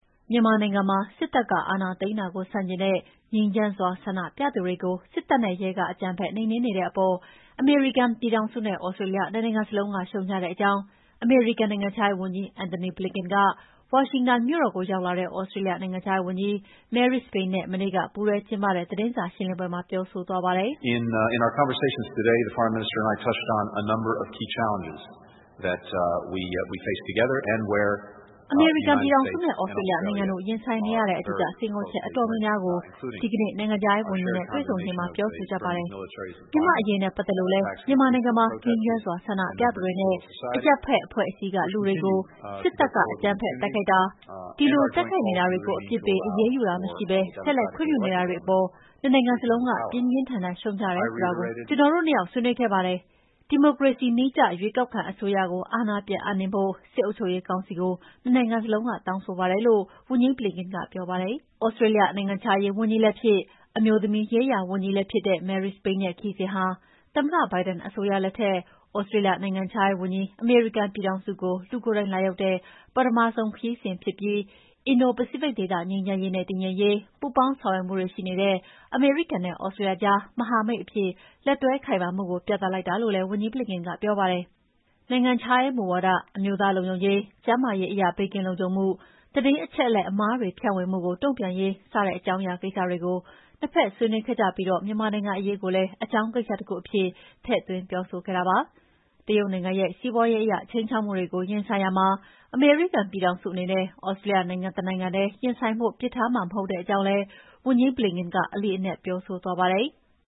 မြန်မာနိုင်ငံမှာ စစ်တပ်ကအာဏာသိမ်းတာကို ဆန့်ကျင်တဲ့ ငြိမ်းချမ်းစွာ ဆန္ဒပြသူတွေကို စစ်တပ်နဲ့ရဲက အကြမ်းဖက်နှိမ်နှင်းနေတဲ့အပေါ် အမေရိကန်ပြည်ထောင်စုနဲ့ သြစတြေးလျ နှစ်နိုင်ငံစလုံးက ရှုတ်ချတဲ့အကြောင်း အမေရိကန်နိုင်ငံခြားရေးဝန်ကြီး Antony Blinken က ဝါရှင်တန်မြို့တော်ကို ရောက်လာတဲ့ သြစတြေးလျနိုင်ငံခြားရေးဝန်ကြီး Marise Payne နဲ့ မနေ့က ပူးတွဲကျင်းပတဲ့ သတင်းစာရှင်းလင်းပွဲမှာ ပြောဆိုသွားပါတယ်။